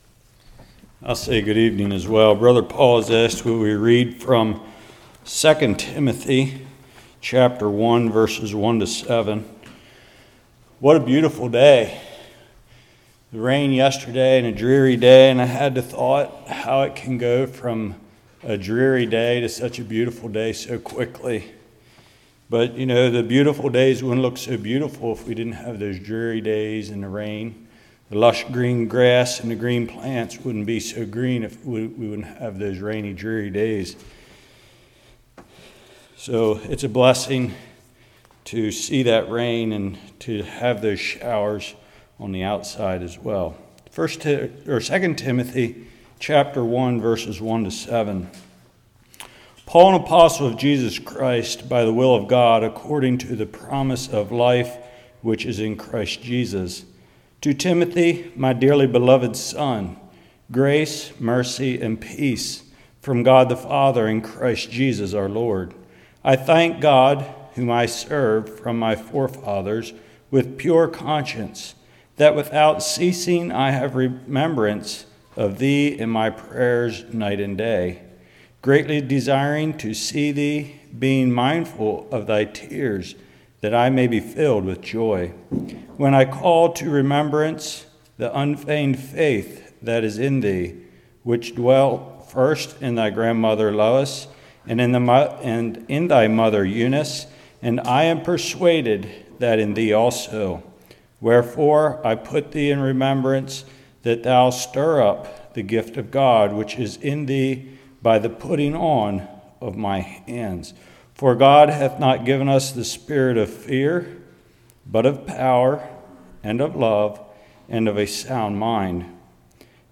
2 Timothy 1:1-7 Service Type: Evening Mother’s are equiped by God to pass on a Christian Heritage.